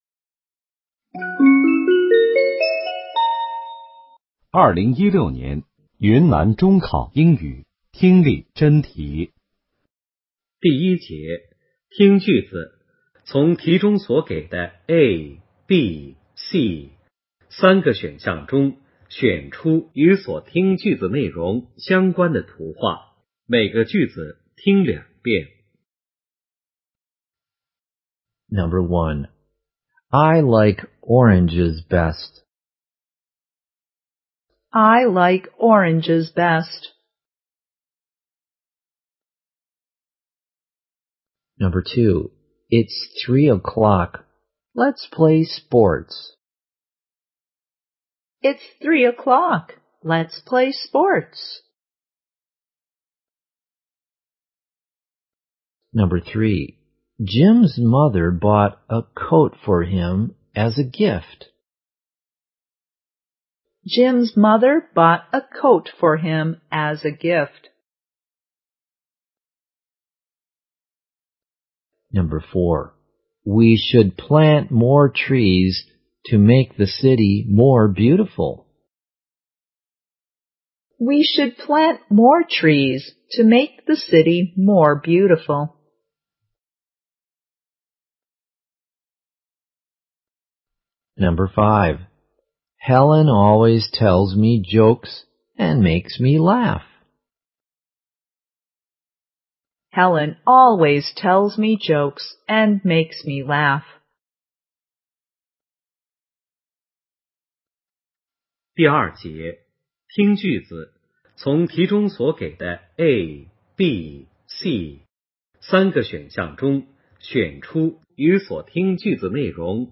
2016年云南中考英语听力：